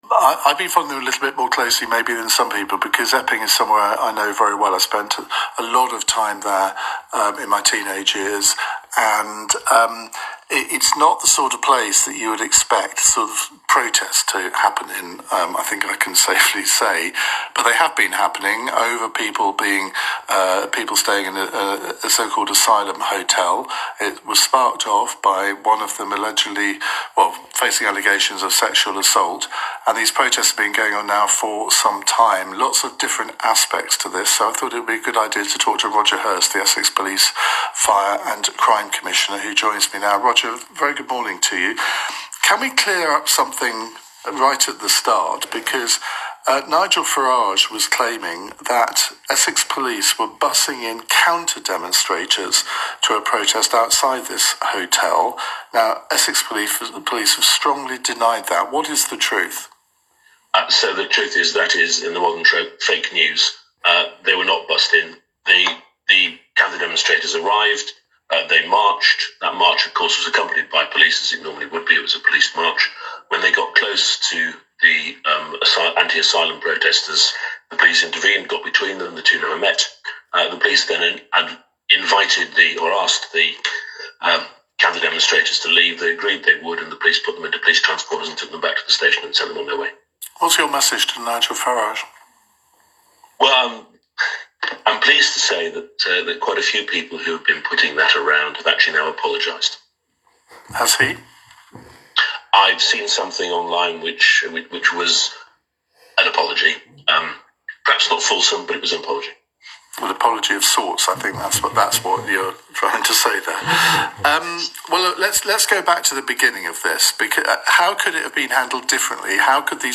This weekend, Police, Fire and Crime Commissioner Roger Hirst spoke with Iain Dale on LBC about the ongoing protests in Epping and the use of the Bell Hotel to house asylum seekers.
Roger Hirst LBC interview 27.7.25
Roger-HIrst-LBC-interview.m4a